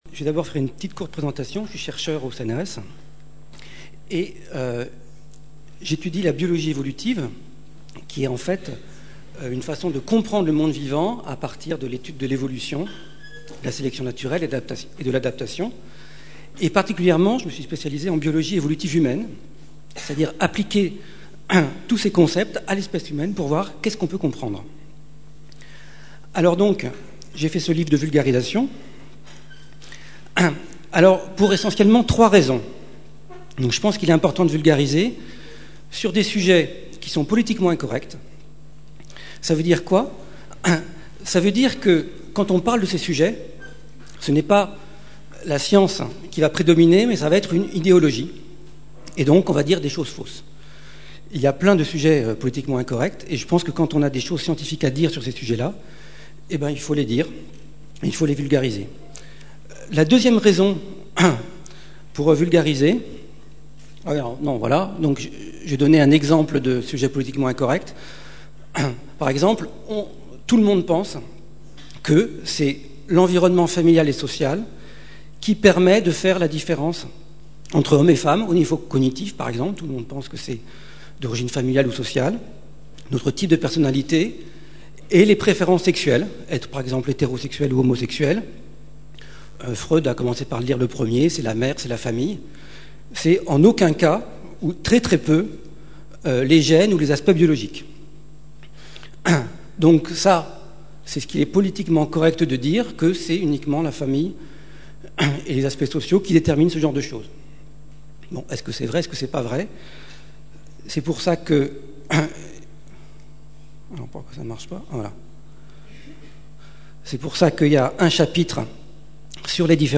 Livres sonores
Rencontre littéraire